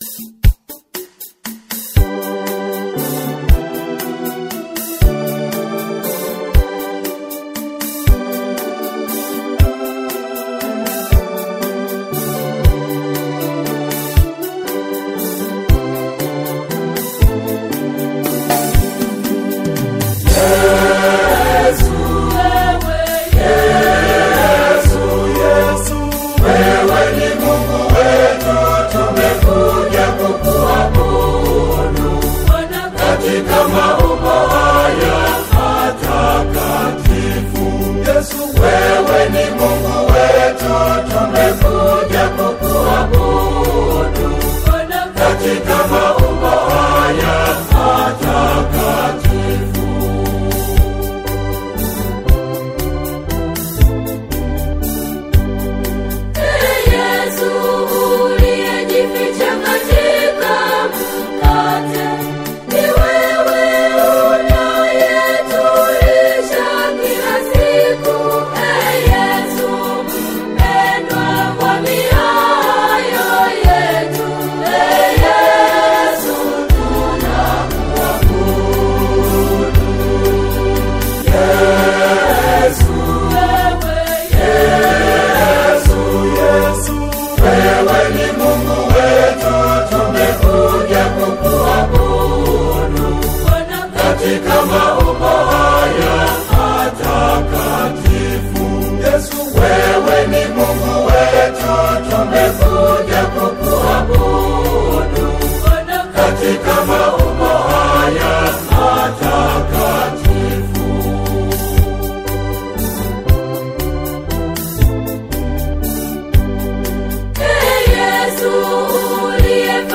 Eucharist song